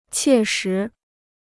切实 (qiè shí): feasible; realistic.